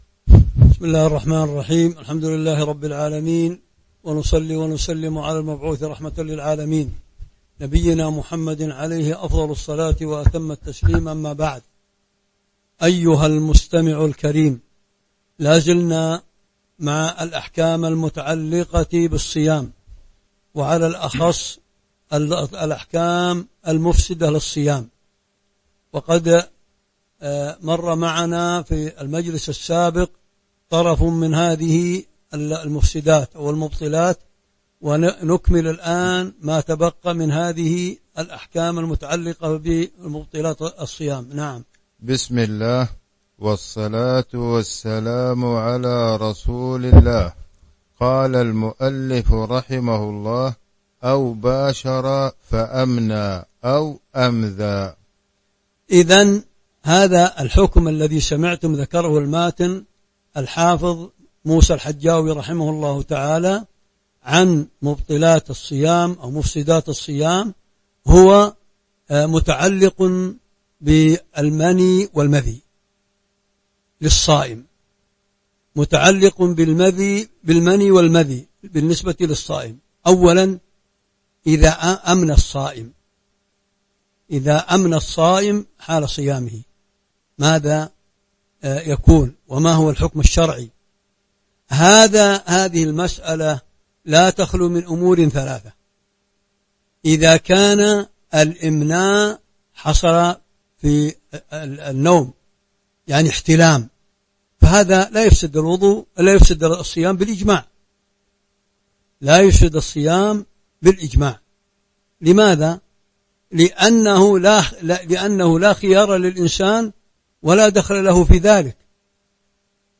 تاريخ النشر ٧ رمضان ١٤٤٢ هـ المكان: المسجد النبوي الشيخ